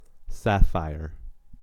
sapphire-us.mp3